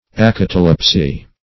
Search Result for " acatalepsy" : The Collaborative International Dictionary of English v.0.48: Acatalepsy \A*cat"a*lep`sy\, n. [Gr.